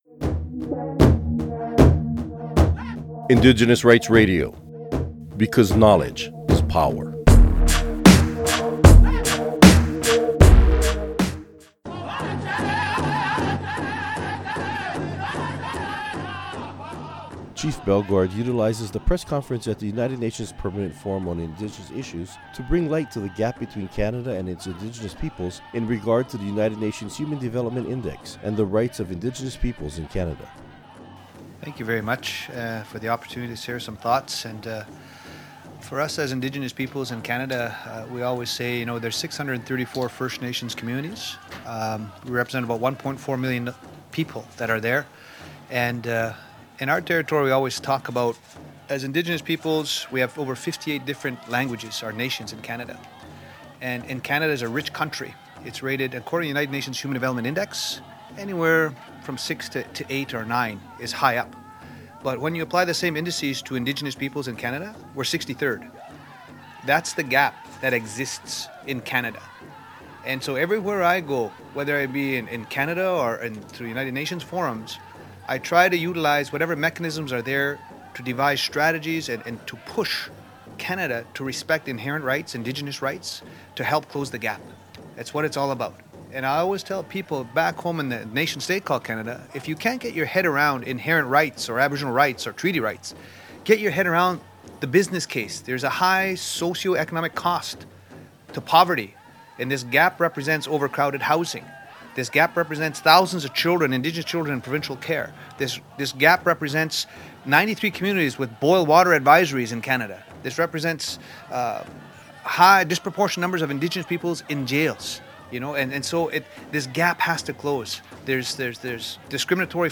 Recording Location: UNPFII 2015
Description: Chief Bellegarde speaks at UNFPII to bring to light the gap between Canada and its Indigenous Peoples in regard to the United Nations Human Development Index and the rights of indigenous peoples in Canada.
Type: Interview